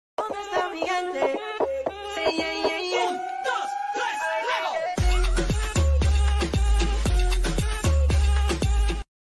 bass boosted remix